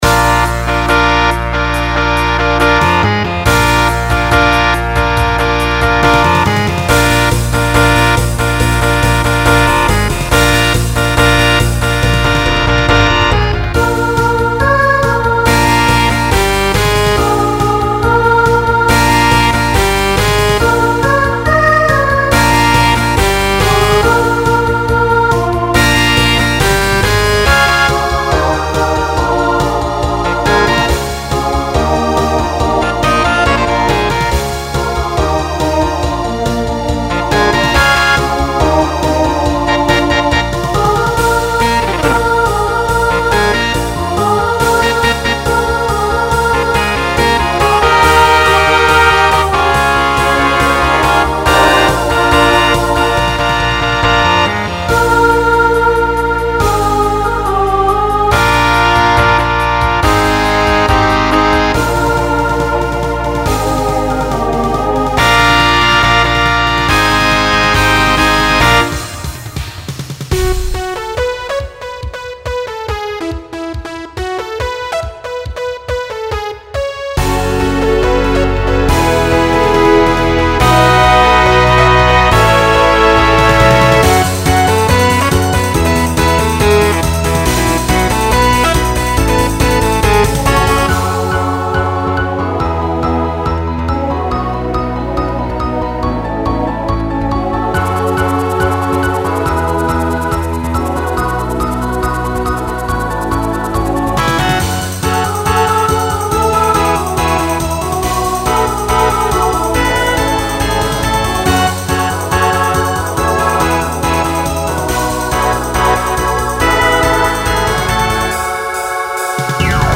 Genre Rock Instrumental combo
Voicing SSA